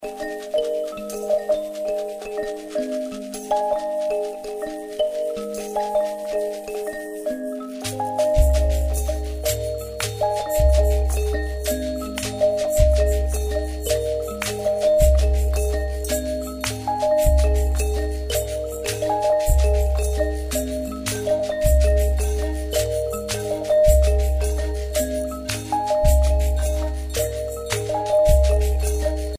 Kalimba & other instruments